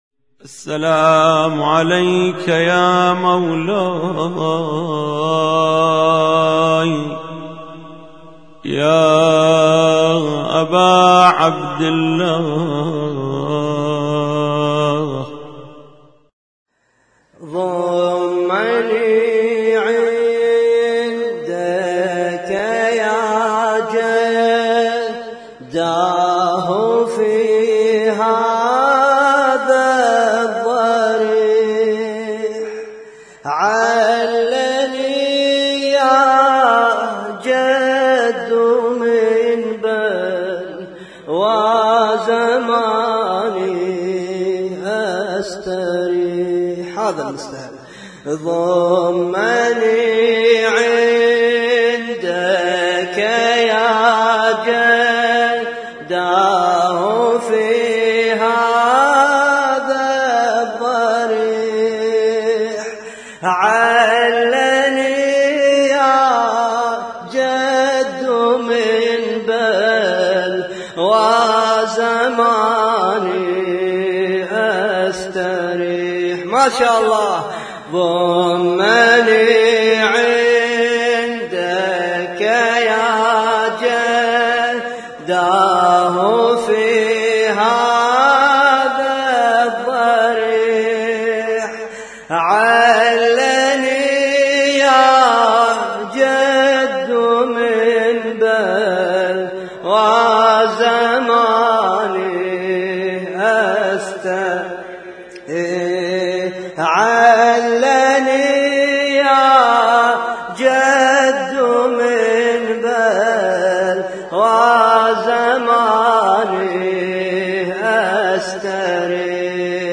لطم ليلة 2 محرم 1436